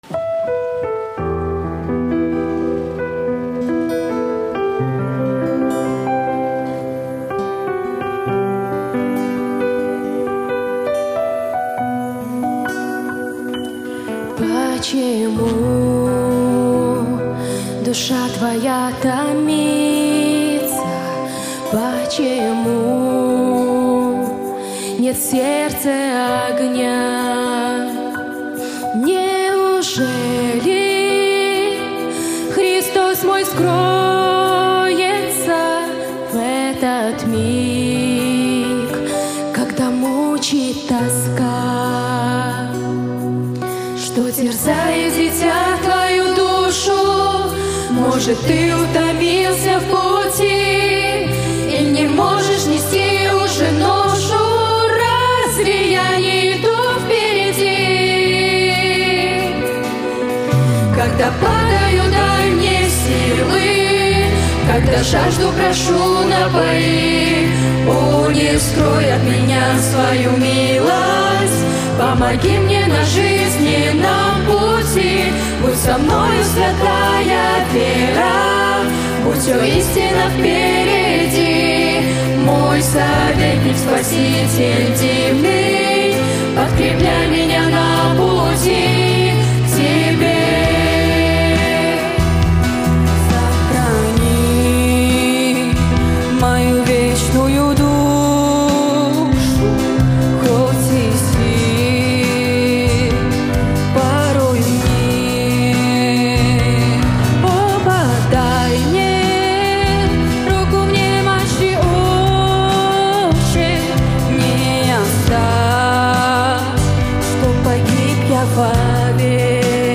Богослужение (ВС, вечер) - 31 августа 2025